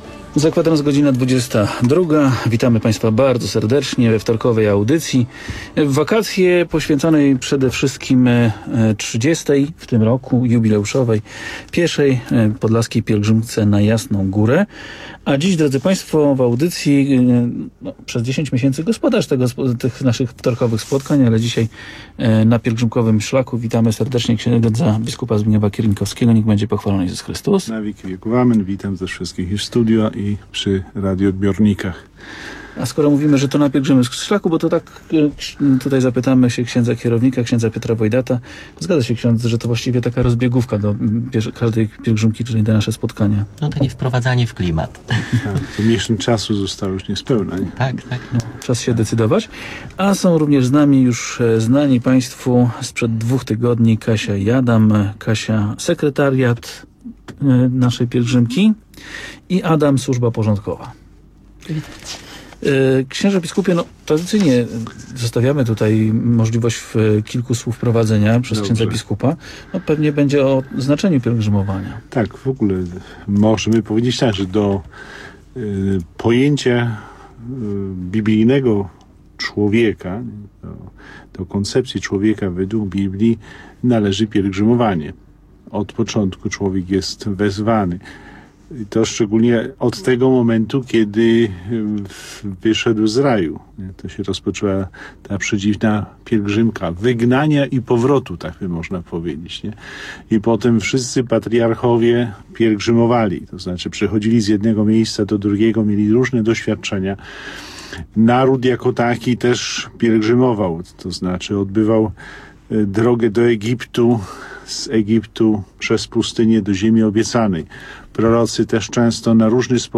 Audycja w KRP (2010-07-06)